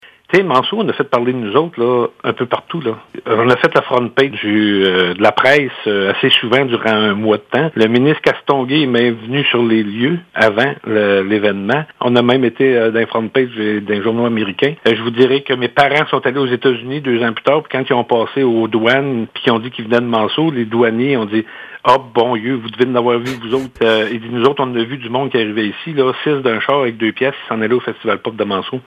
Malgré le «flop», la municipalité entend souligner le 50e anniversaire du Festival Pop, l’an prochain, étant donné que l’événement avait mis Manseau sur la carte, comme rappelle le maire Guy Saint-Pierre.